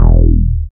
69.10 BASS.wav